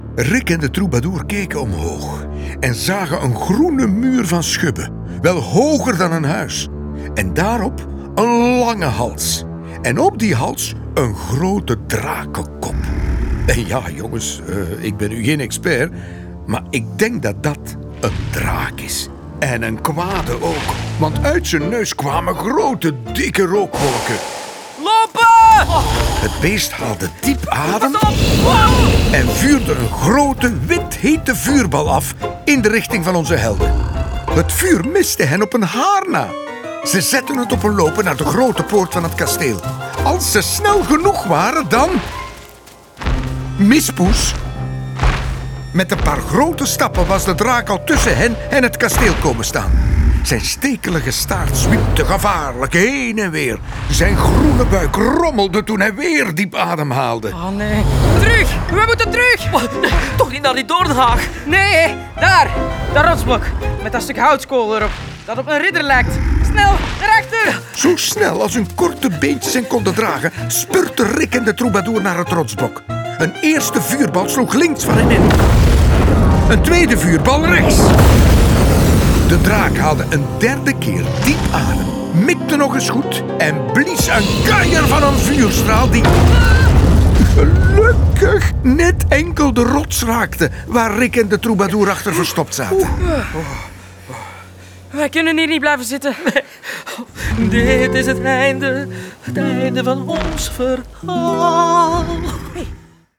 Tekst (hoorspel en boek): Koen Van Deun